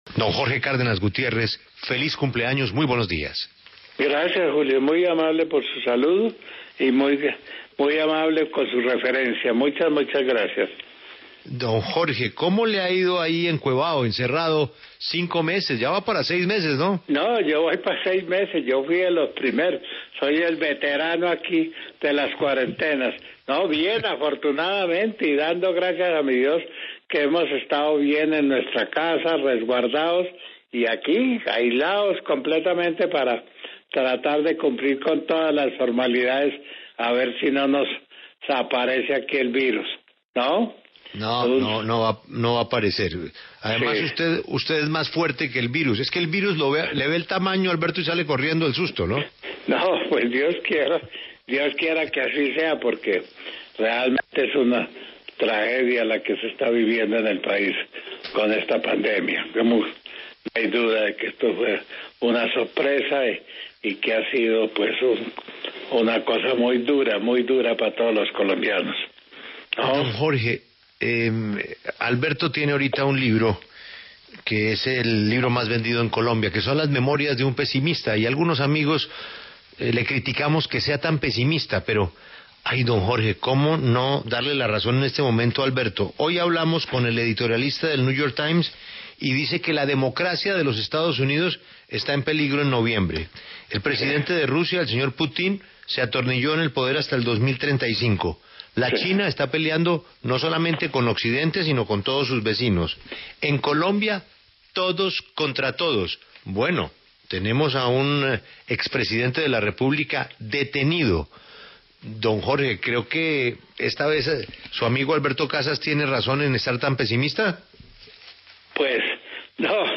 Reviva la entrevista en La W con Jorge Cárdenas, exgerente de la Federación Nacional de Cafeteros